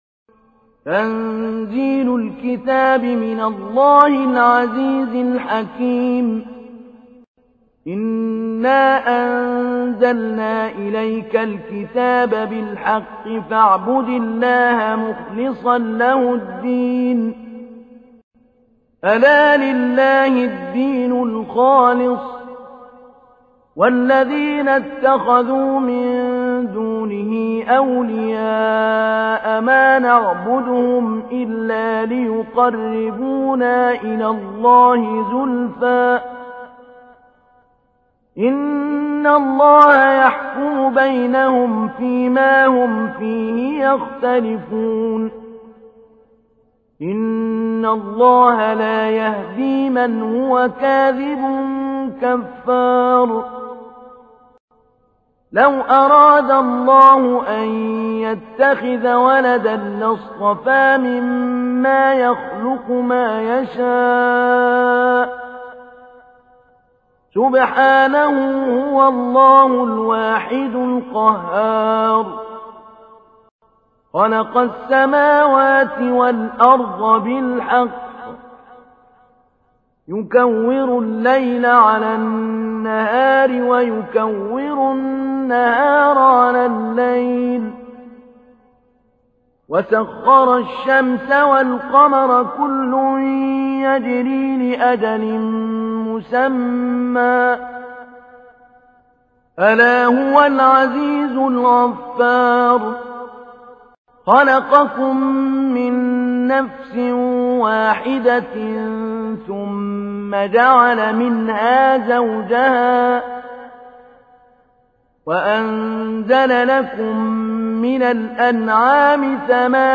تحميل : 39. سورة الزمر / القارئ محمود علي البنا / القرآن الكريم / موقع يا حسين